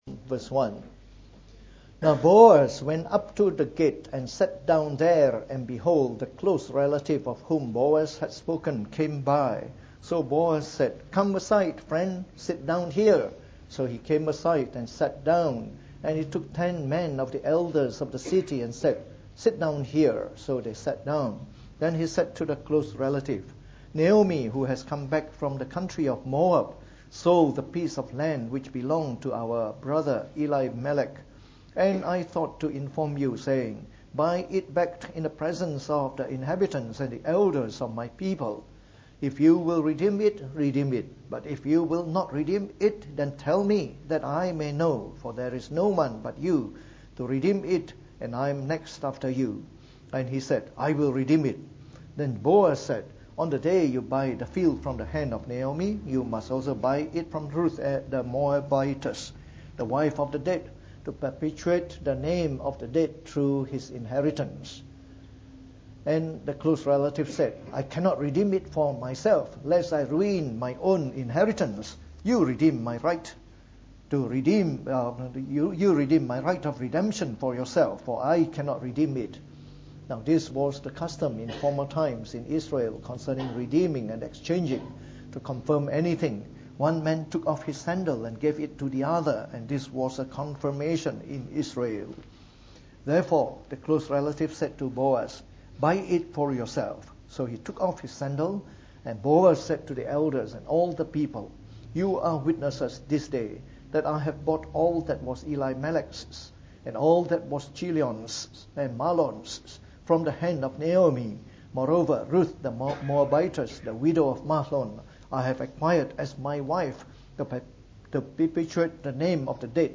From our series on the Book of Ruth delivered in the Morning Service.